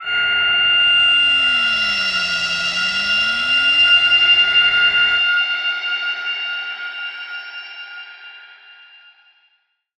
G_Crystal-F8-mf.wav